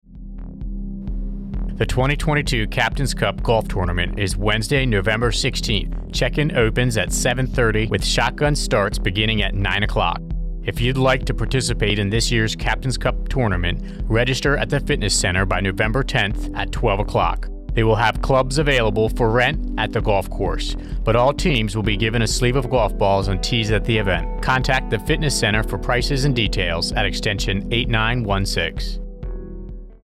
Thirty-second commercial spot highlighting MWR Bahrain's Captain's Cup Golf Tournament, to be aired on AFN Bahrain’s morning and afternoon radio show.